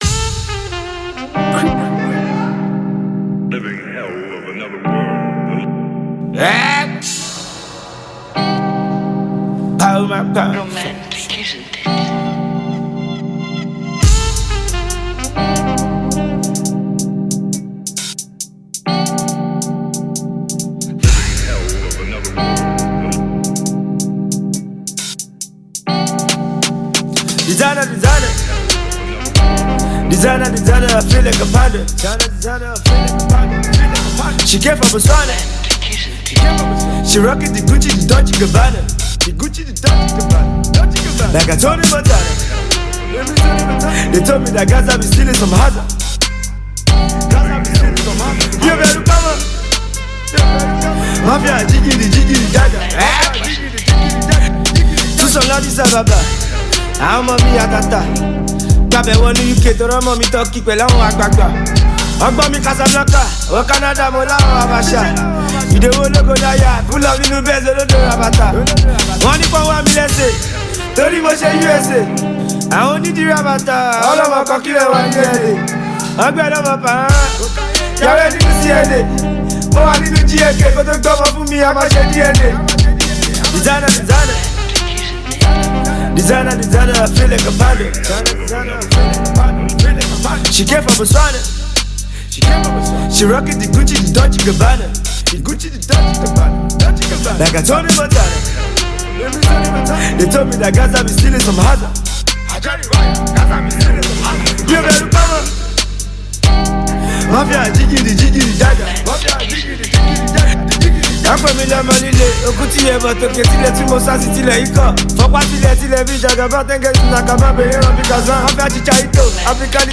Popular Nigerian street rapper and singer